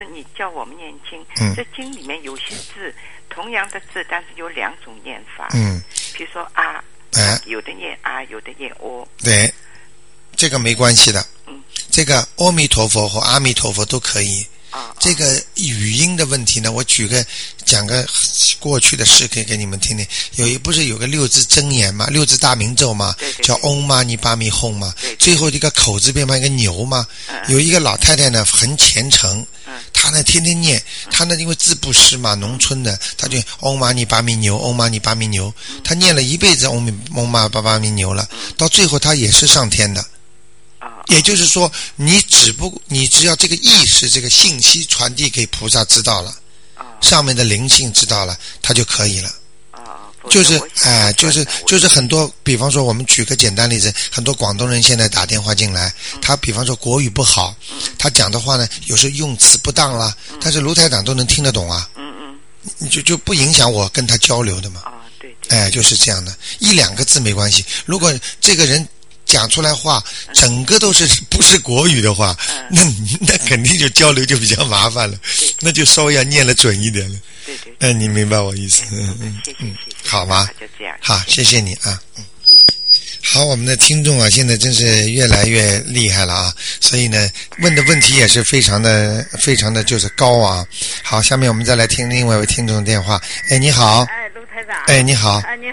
目录：☞ 2008年04月_剪辑电台节目录音集锦